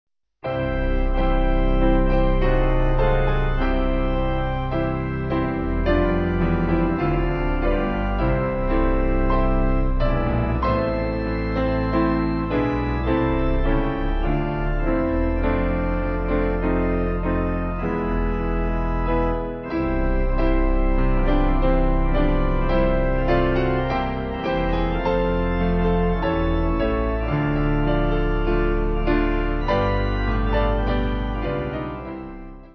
Organ/Piano Duet